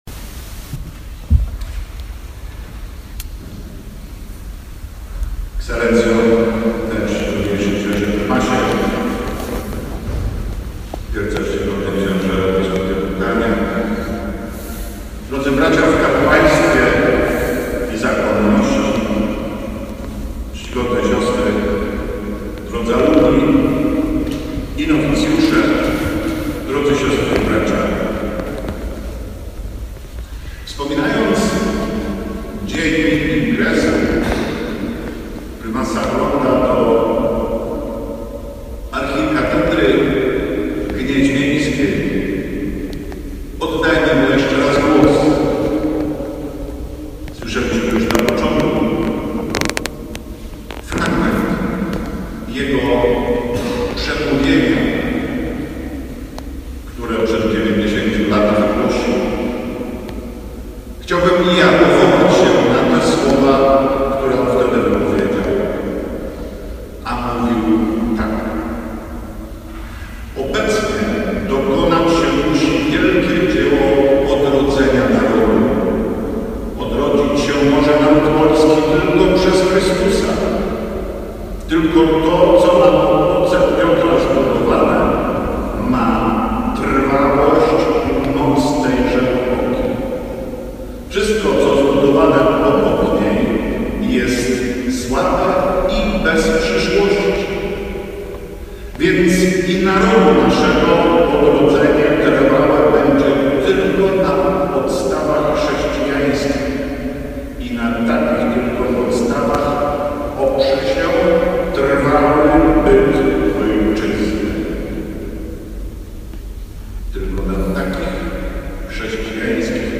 Msza z okazji 90. rocznicy ingresu kard. A. Hlonda do katedry w Gnieźnie
h_kazanie_ks_generala_90_ingresu.mp3